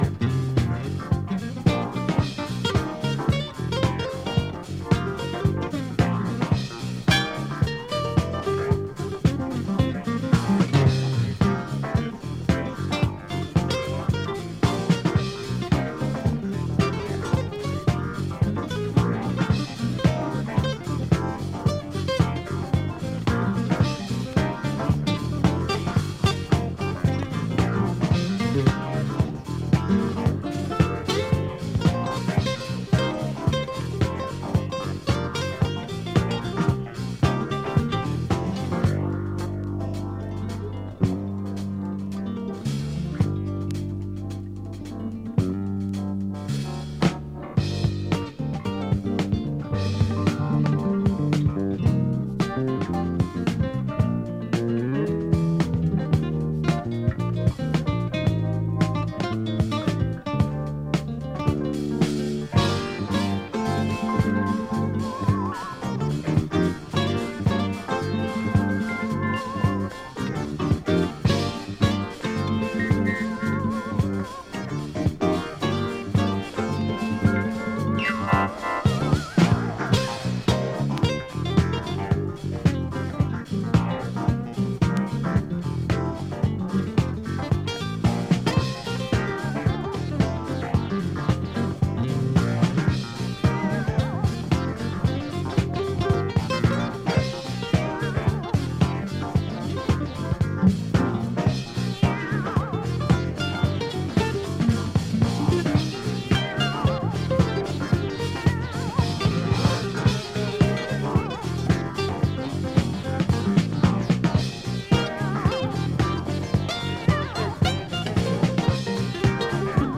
mixing jazz and Chicago house
jazz funk jam!